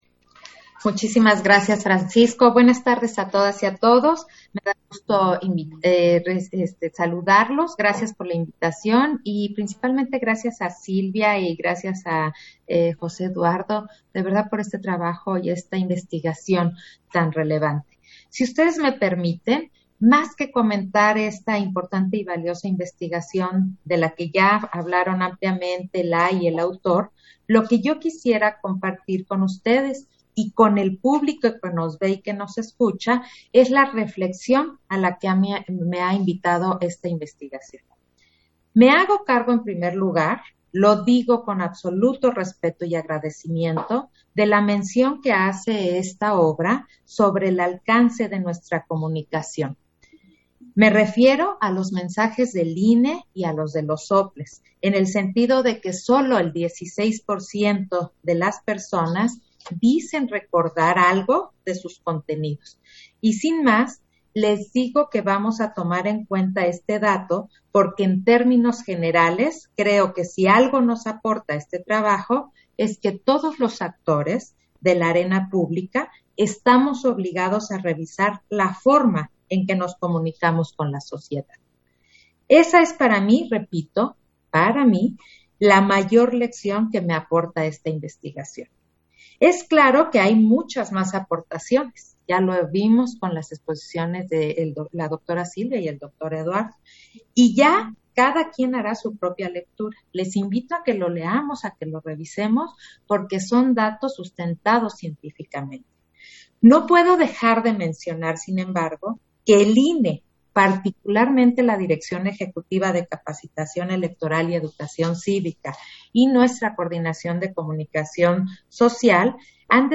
Intervención de Claudia Zavala, en la presentación editorial, La confianza y la participación de la juventud en la democracia